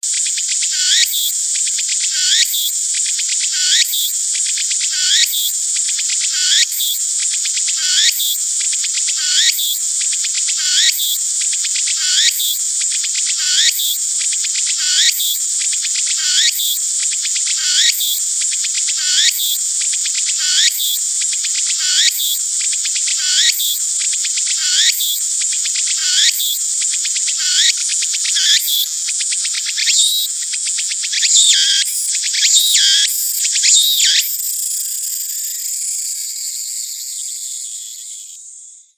ツクツクボウシ（つくつく法師）の鳴き声 着信音